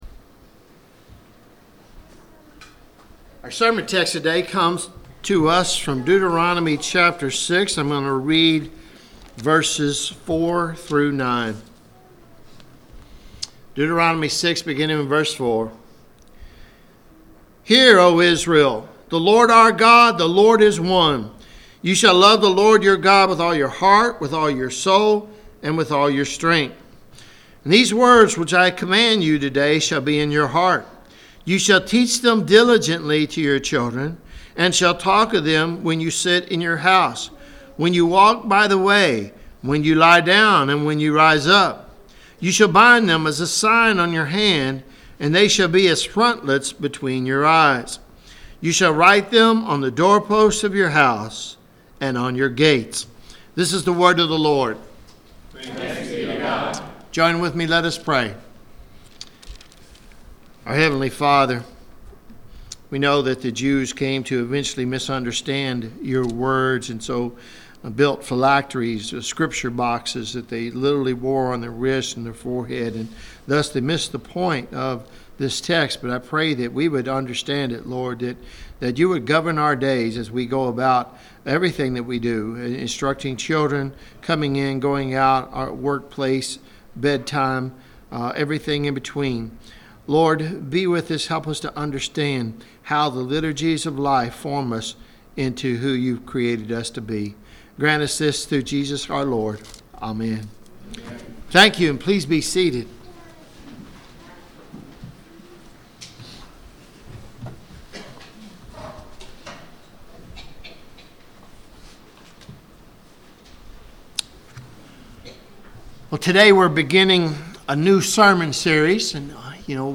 at Christ Covenant Presbyterian Church, Lexington, Ky.